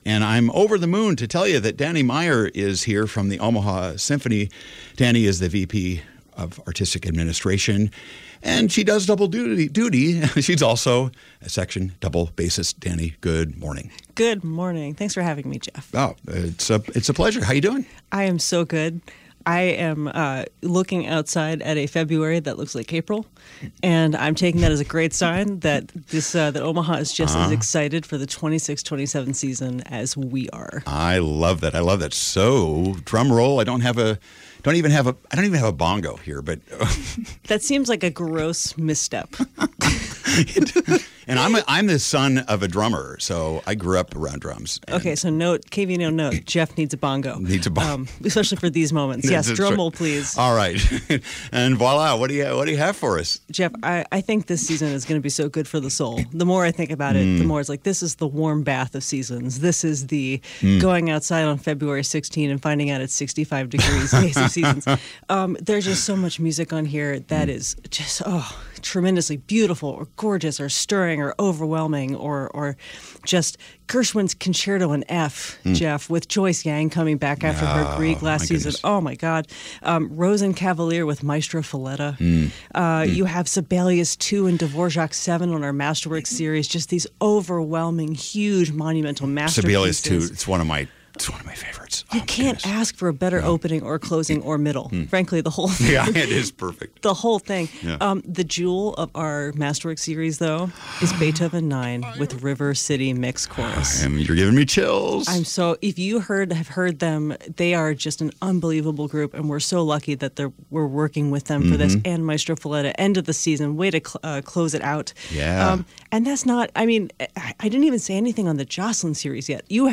The Omaha Symphony visited KVNO to do a live reveal of their new season, which features a vibrant mix of masterworks, pops and other exciting concerts for our Omaha community.